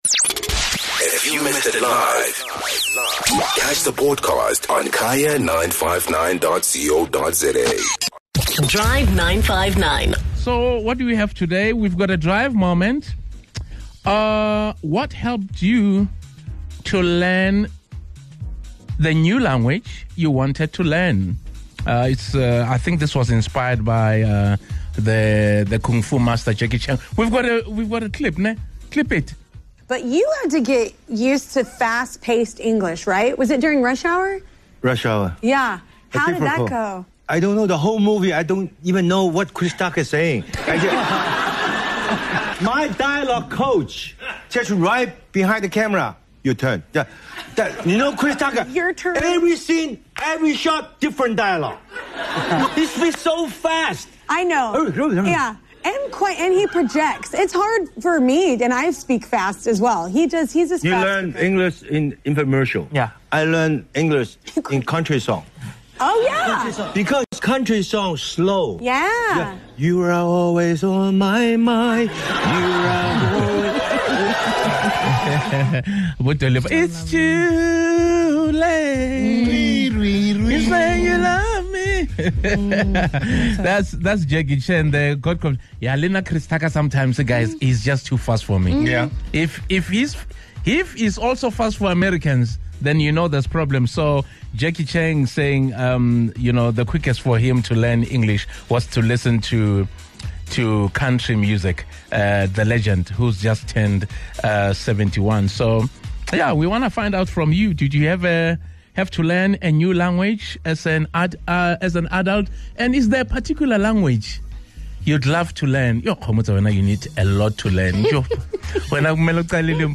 Listen in to hear what our Drive 959 team and our listeners had to say.